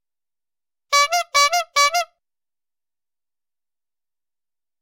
Звуки велосипедного звонка
Звук велосипедного гудка клоуна для шуток